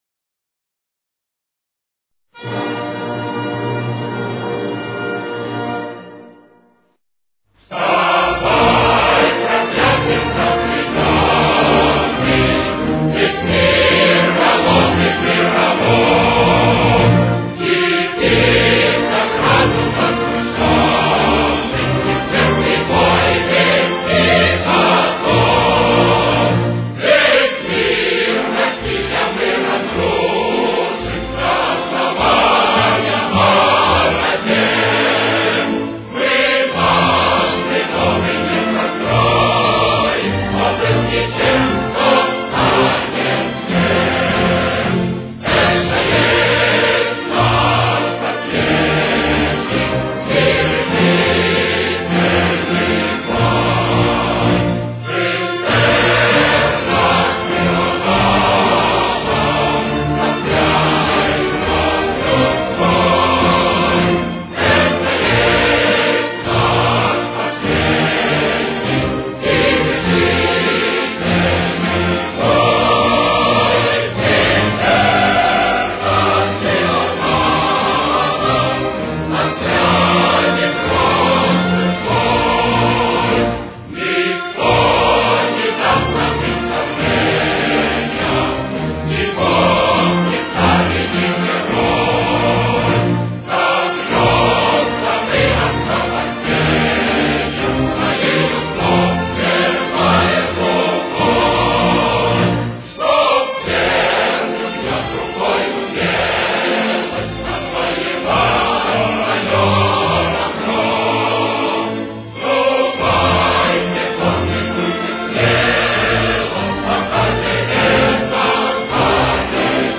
Темп: 103.